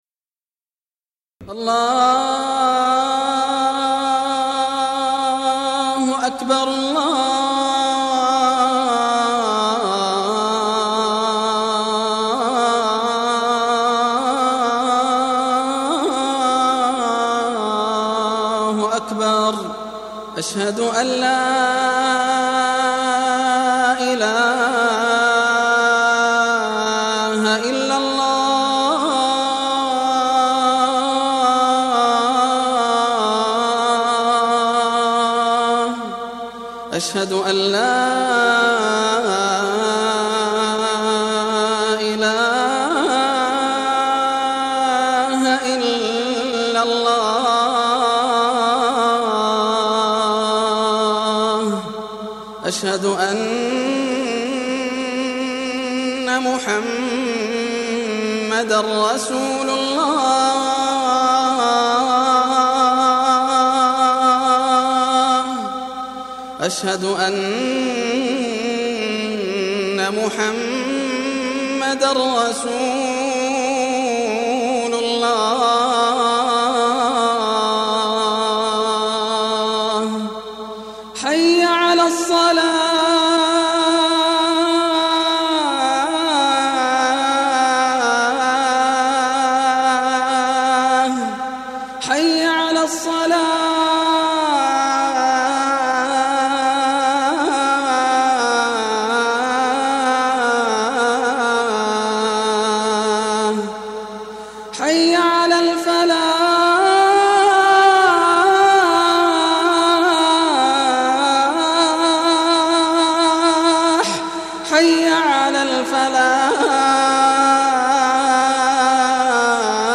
آذان/ ياسر الدوسري - المكتبة الإسلامية